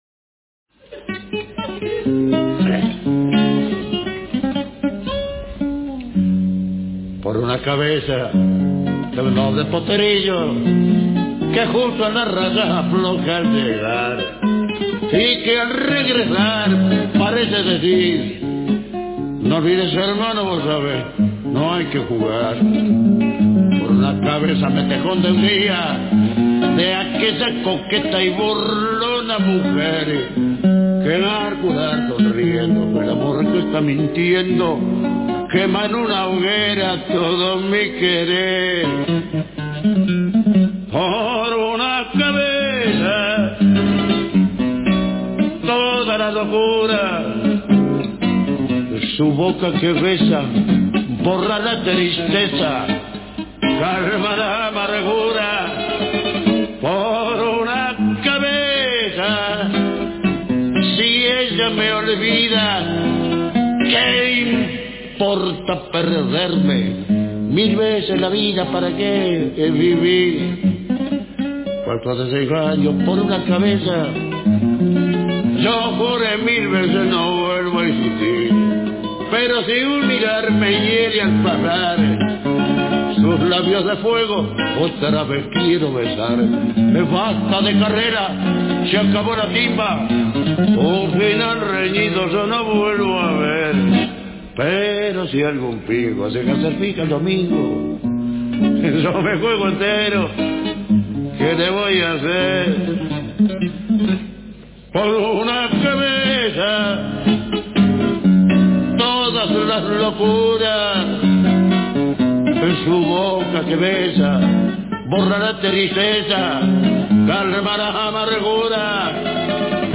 Letra del Tango